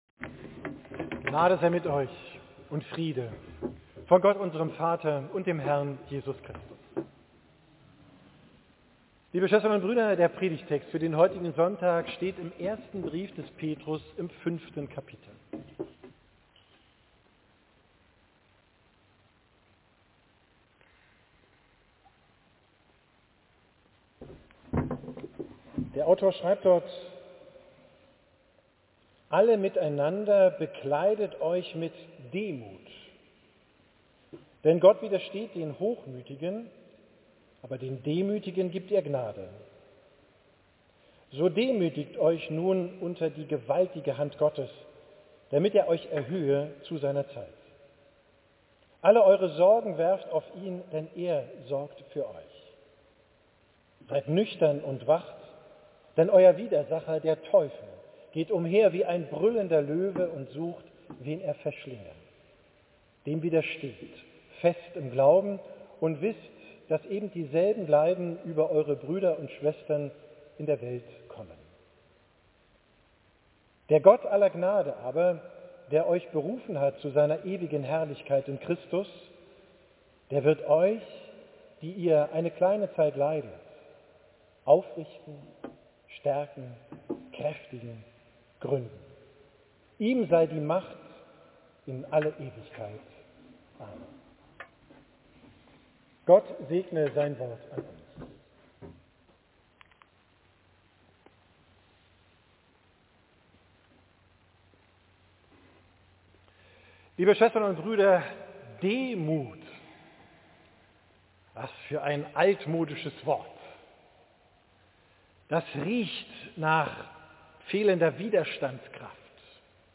Predigt vom 15.